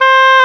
WND OBOE-D.wav